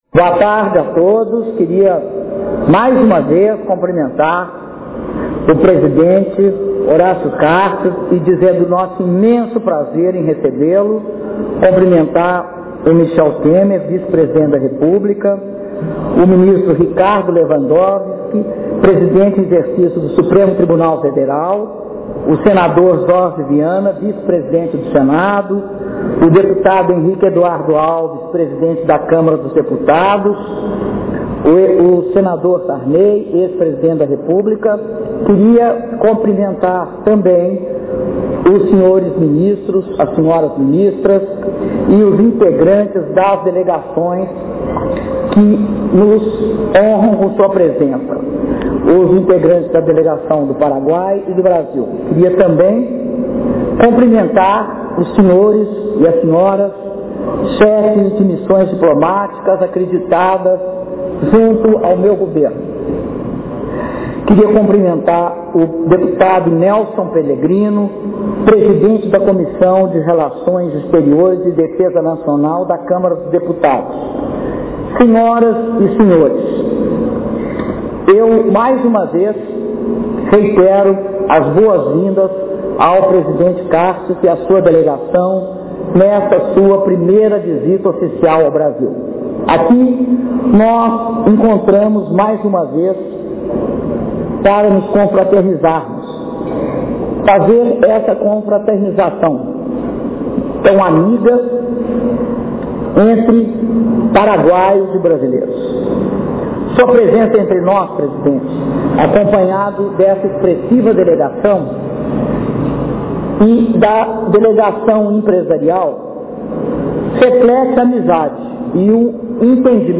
Áudio do discurso da Presidenta da República, Dilma Rousseff, durante almoço em homenagem ao Presidente da República do Paraguai, Horacio Cartes - Brasília/DF (11min29s)